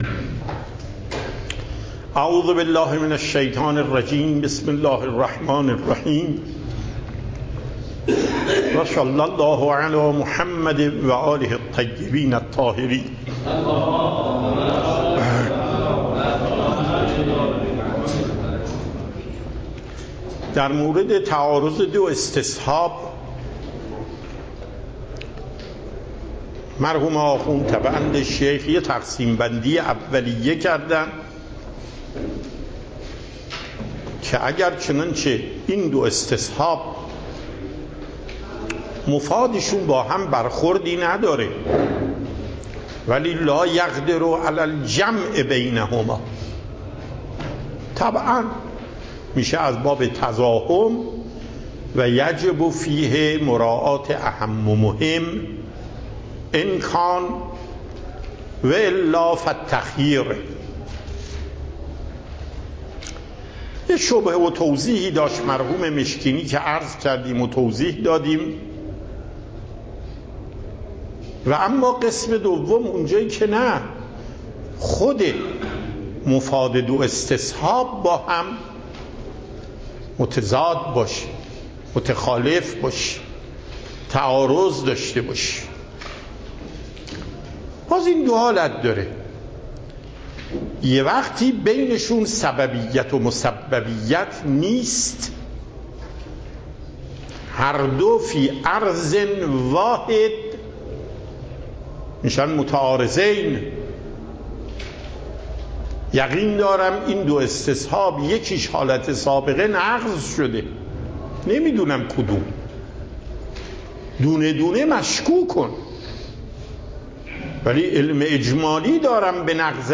صوت و تقریر درس پخش صوت درس: متن تقریر درس: ↓↓↓ تقریری ثبت نشده است.
درس اصول آیت الله محقق داماد